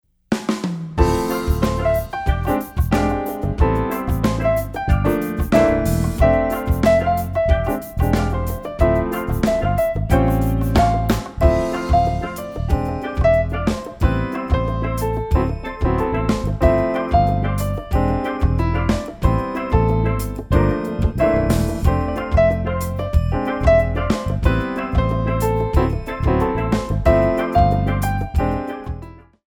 8 bar intro
Samba / Jazz